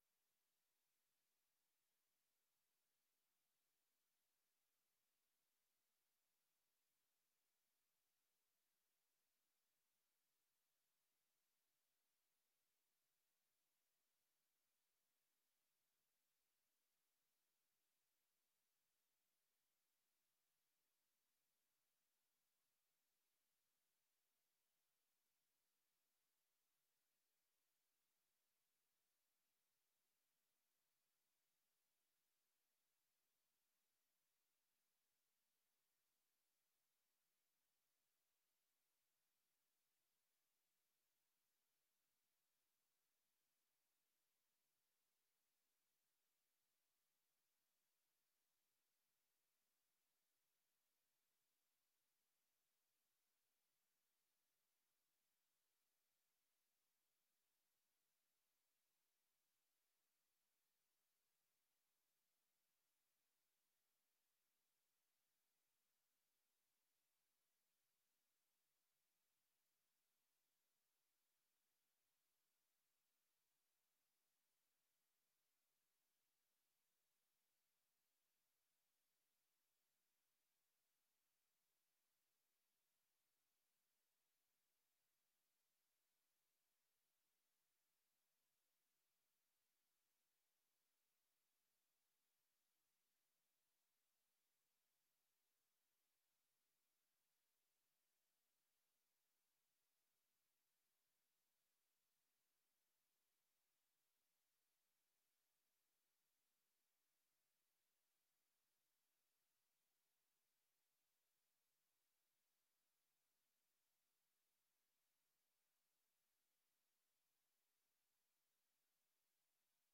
Dit betreft de heropening van de op 6 november jl. geschorste raadsvergadering.
Locatie: Raadzaal